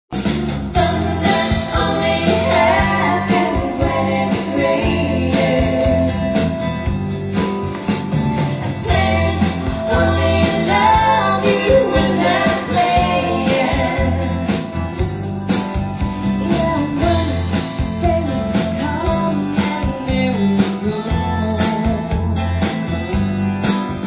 (Live in concert)